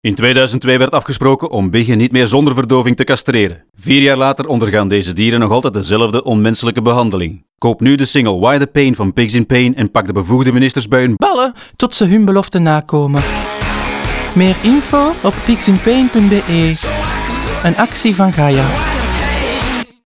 Dat de spot humoristisch bedoeld is, maakt de verandering in toonhoogte vanaf het woord ‘ballen’ duidelijk.